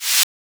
Shaker Groovin 3.wav